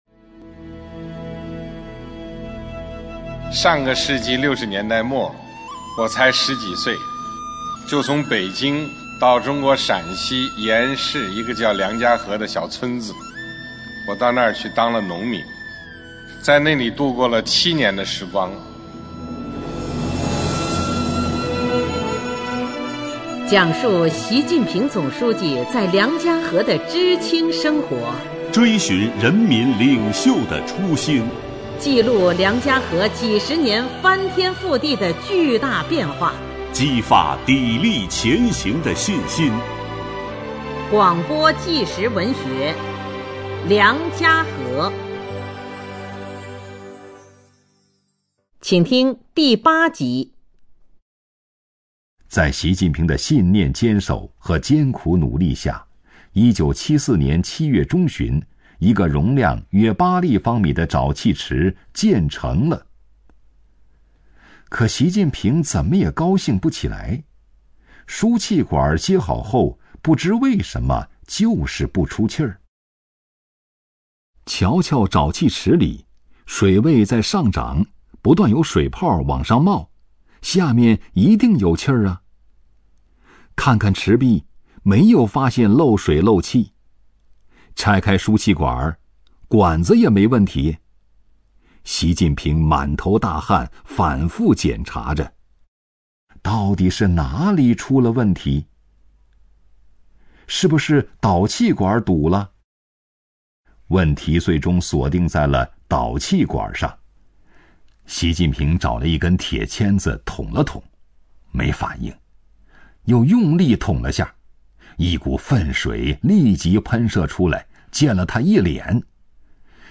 广播纪实文学《梁家河》第八集：要为人民做实事 - 戏剧影视学院